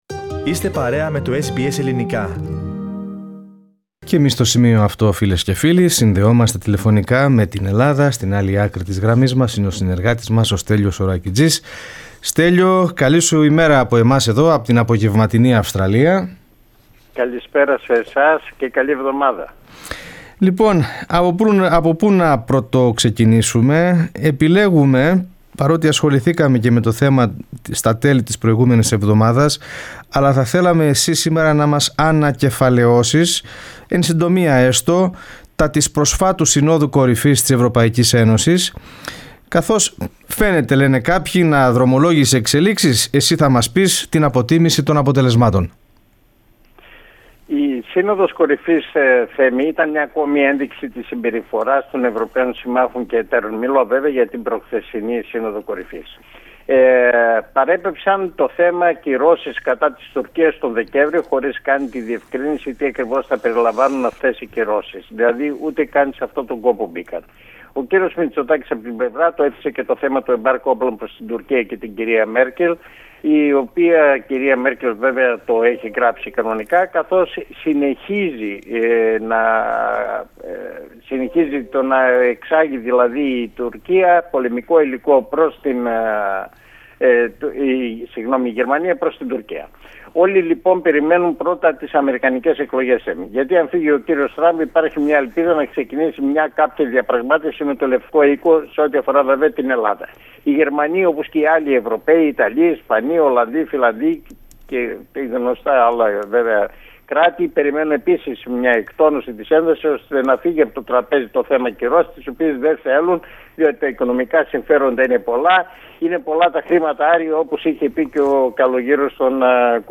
Η εβδομαδιαία ανταπόκριση από την Ελλάδα (19.10.2020)